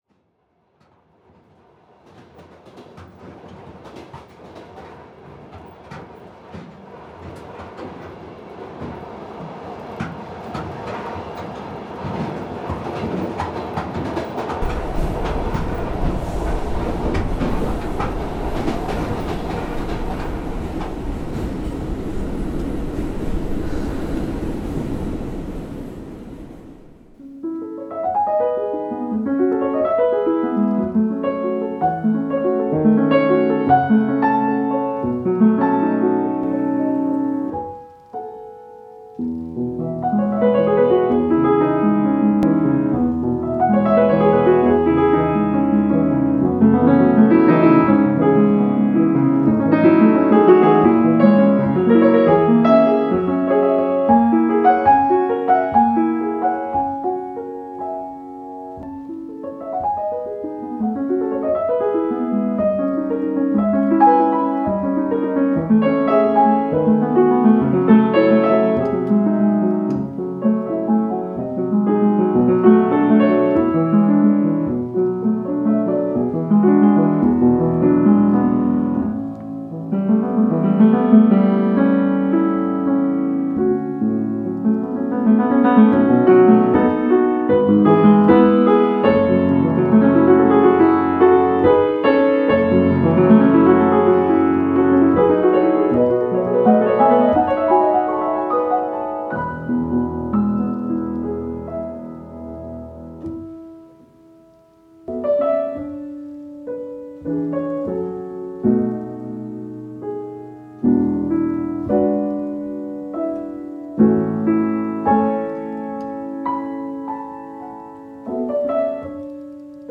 metallic interferences (debussy deux arabesque - with sound effect)